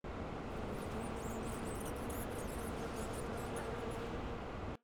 楼道场景1.wav